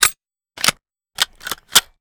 minigun_reload_02.wav